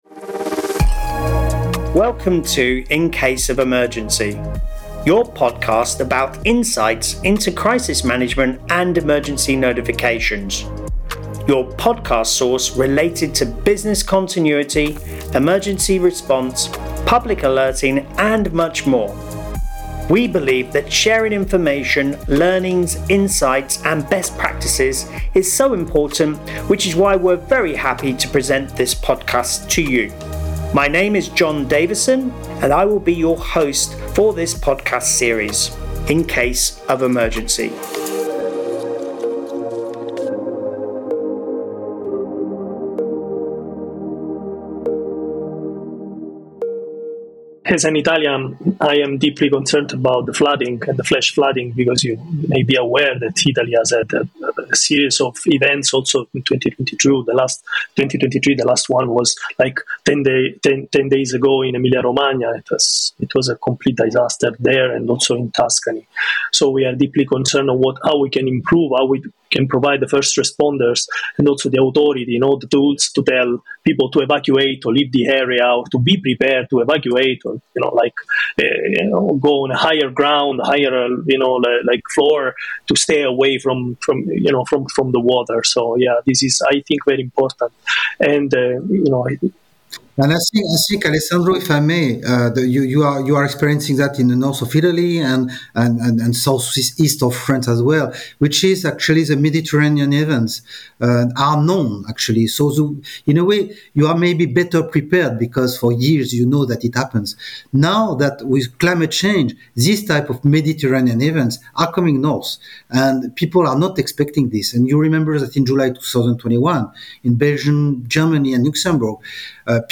is in conversation with